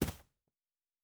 Footstep Carpet Running 1_08.wav